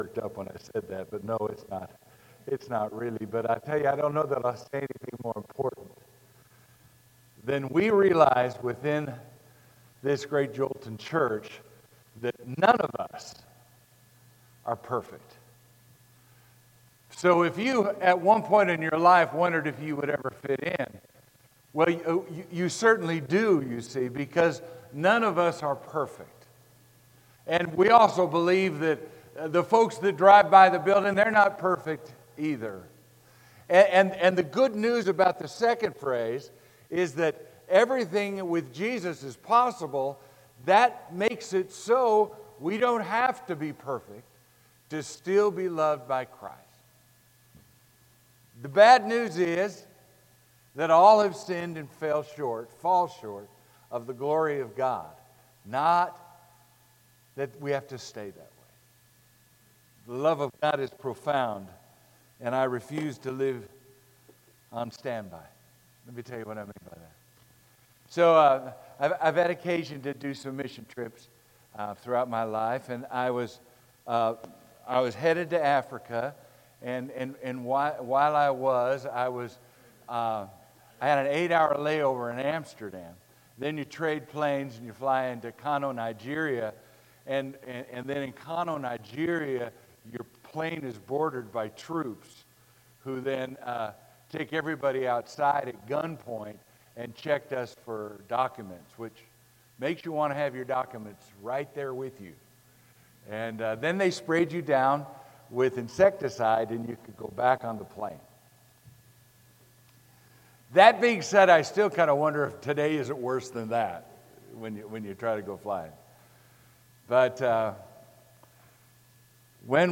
Sermon: Living On Standby